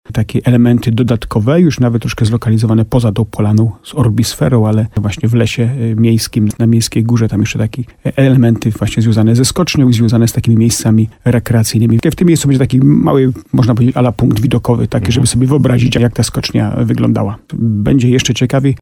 Jak mówi burmistrz Starego Sącza Jacek Lelek podpisano już umowę z wykonawcą.